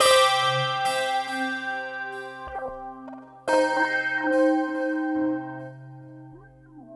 Clang_69_C.wav